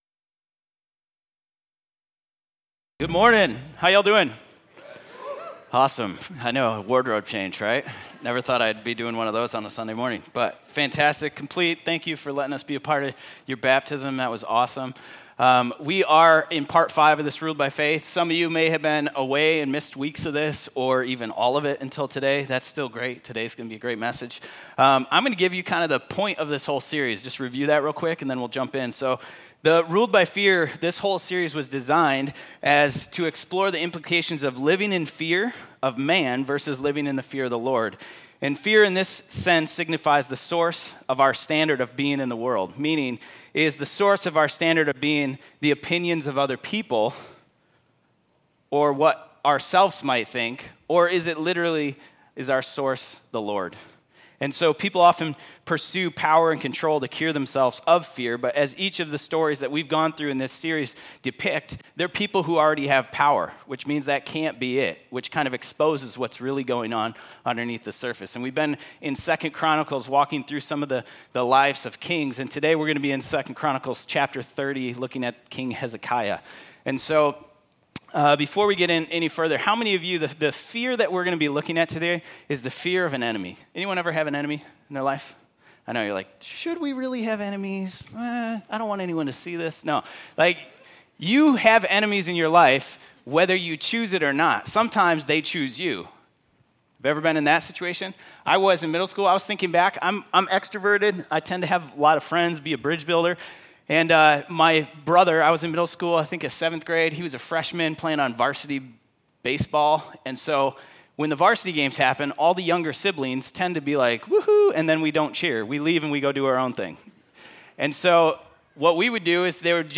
Audio Sermon Save Audio https